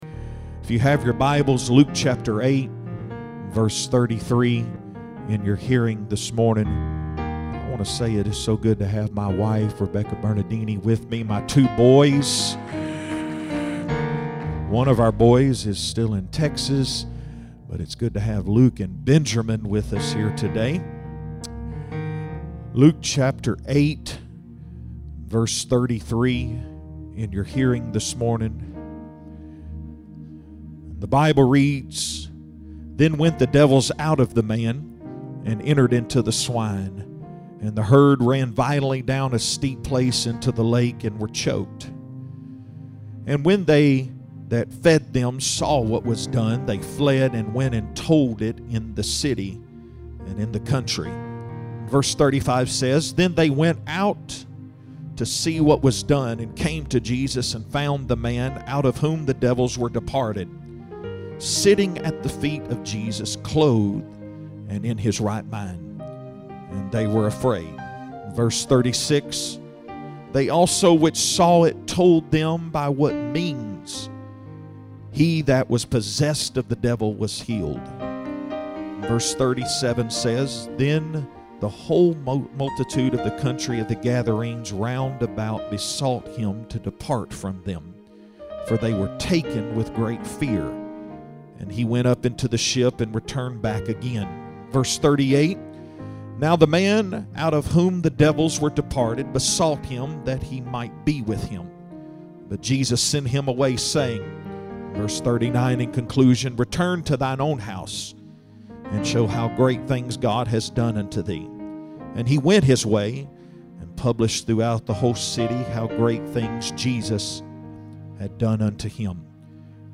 Join us for a compelling sermon delivered by our guest speaker